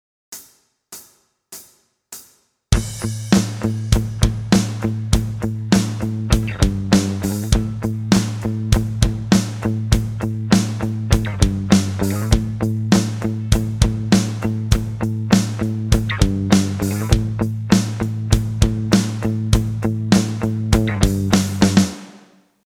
A Minor Backing Track